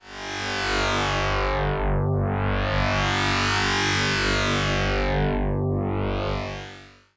synth02.wav